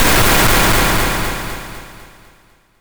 lightning.wav